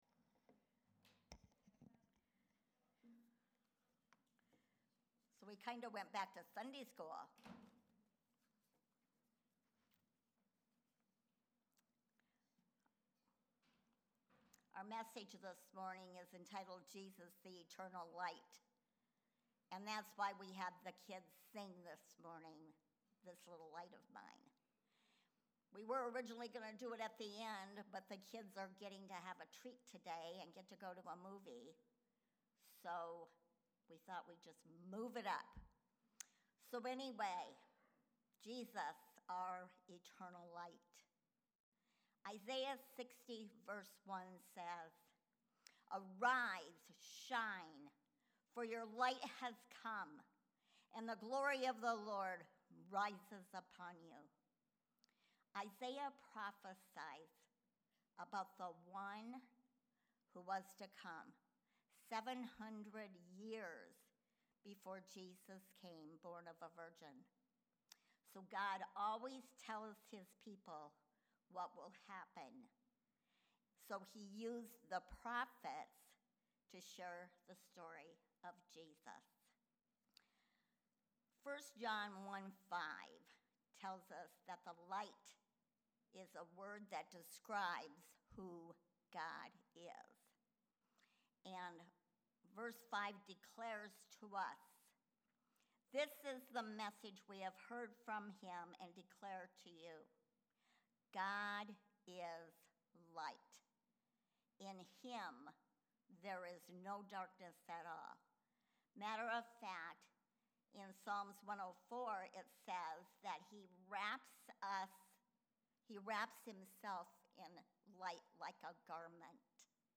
Sermons | Friendship Assembly of God